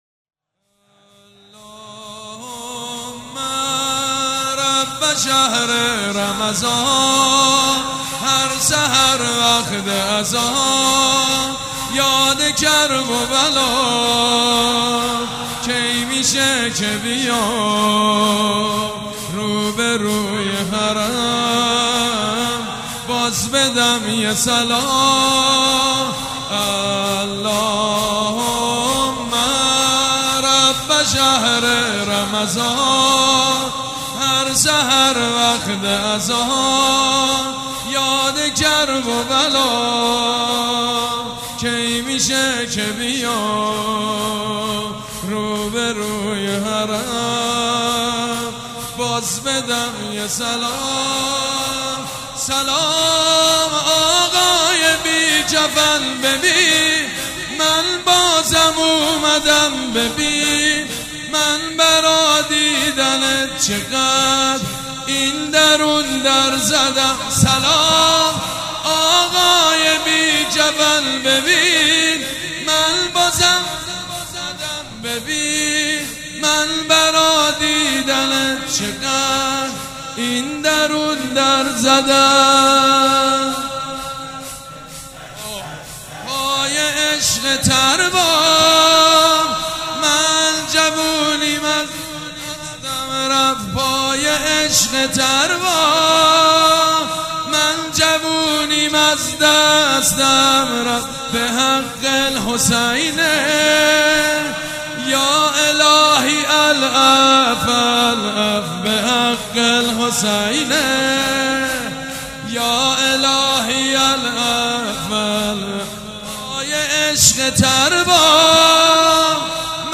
گلچین مناجات و مداحی شب نوزدهم و بیست و یکم ماه مبارک رمضان سال1397 با نوای حاج سید مجید بنی فاطمه
بنی-فاطمه-شب-نوزدهم-ماه-رمضان-شور9706-1.mp3